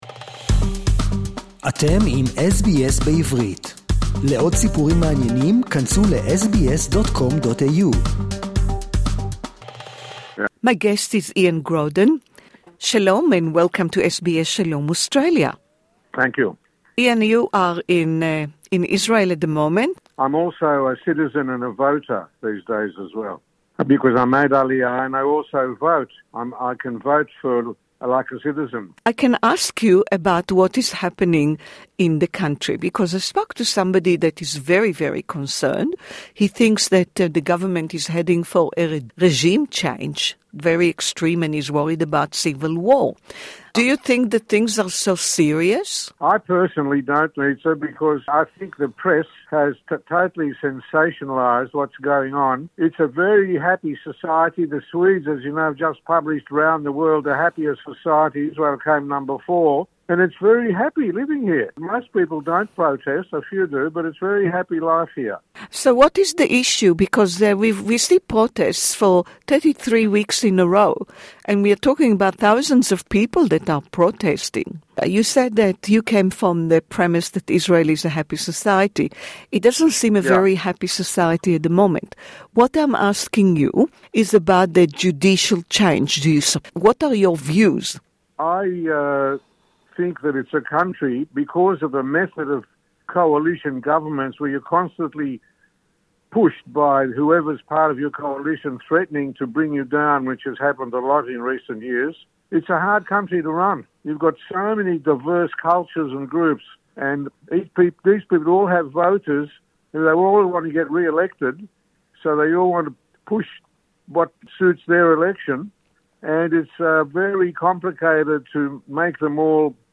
who is actually supporting a judicial change and is not worried at all about the Netanyahu Gov. this interview is in English Share